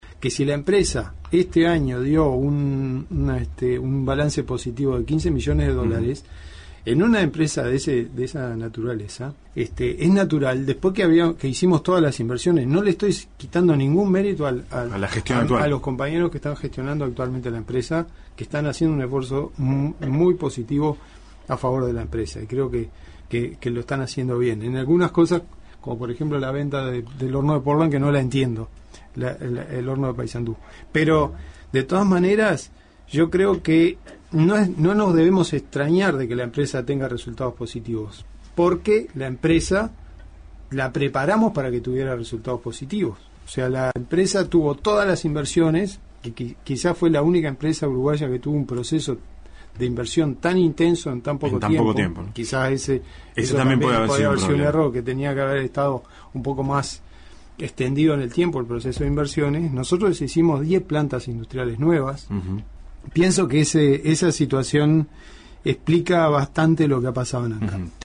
Entrevistado en M24, habló de ANCAP y dijo que la ganancia de 15 millones de dólares registrada en 2016 se debe a las inversiones realizadas durante su gestión.